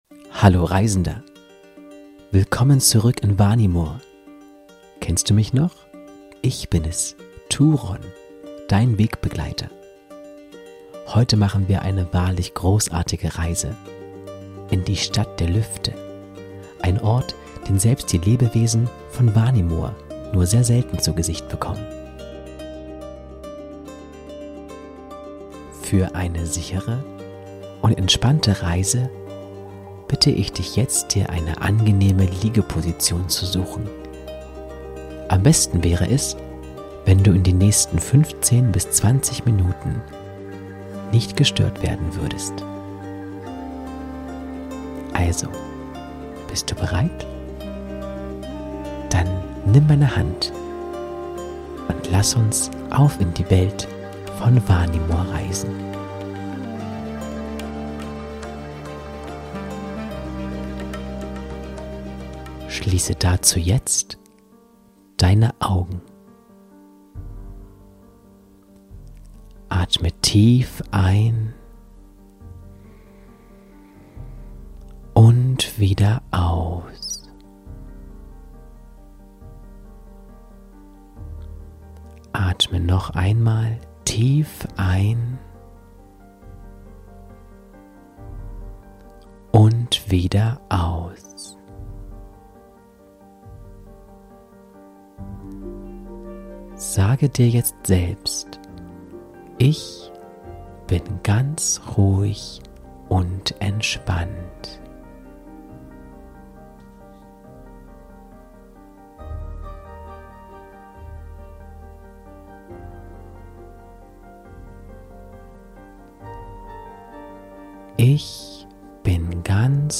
Entspannungsgeschichte: Reise nach Vanimor - Stadt der Lüfte ~ Vanimor - Seele des Friedens Podcast